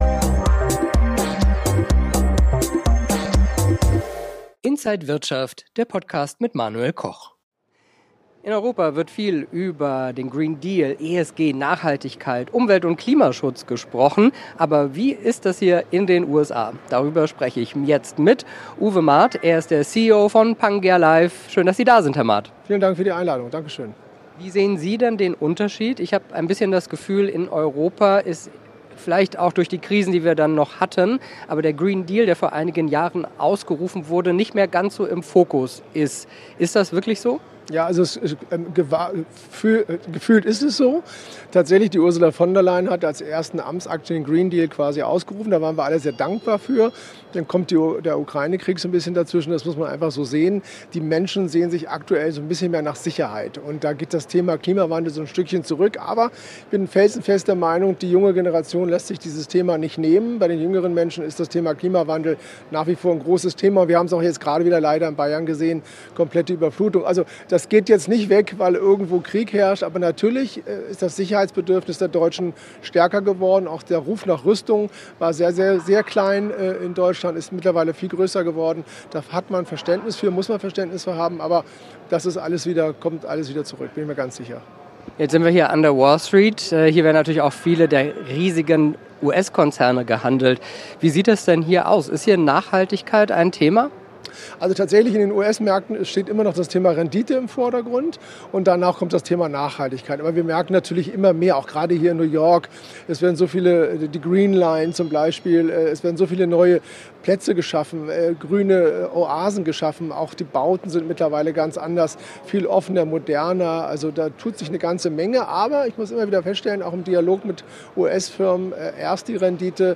Alle Details im Interview